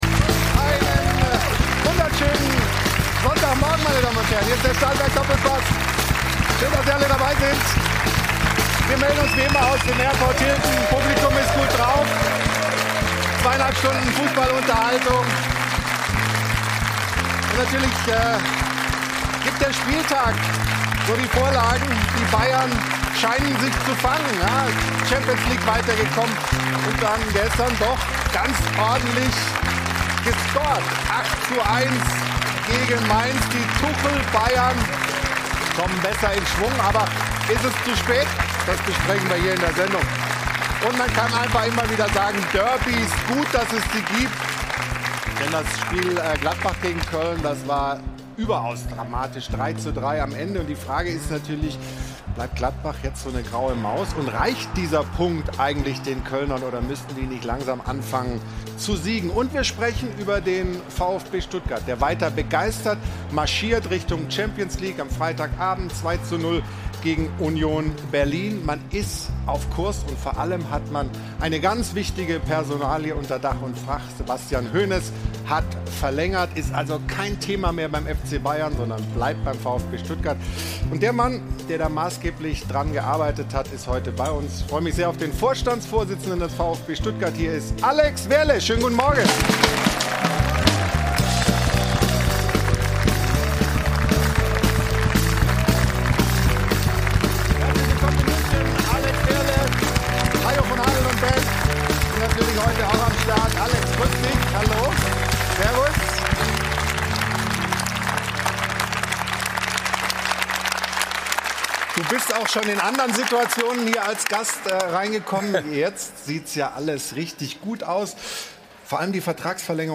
In der neuen Folge des Doppelpass diskutieren sie unter anderem über die Themen: